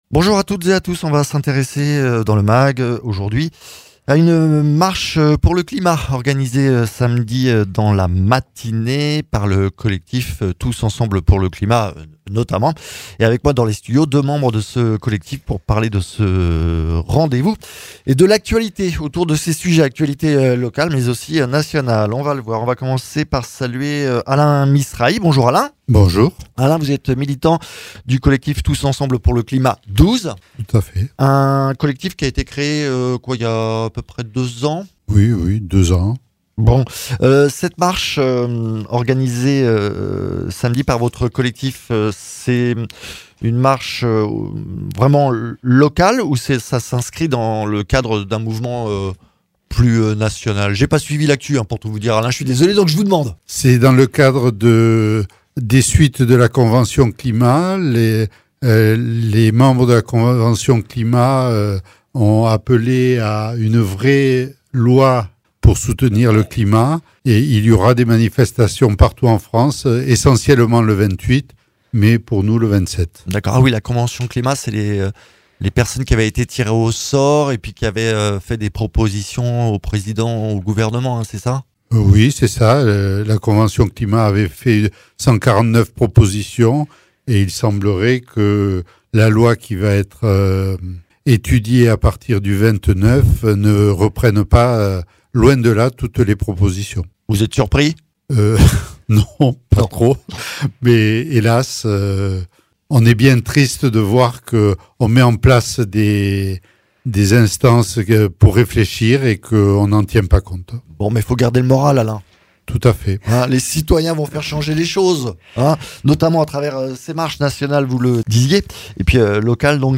membres du collectif 12 ensemble pour le climat